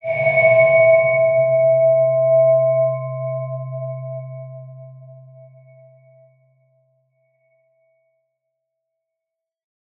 X_BasicBells-C1-ff.wav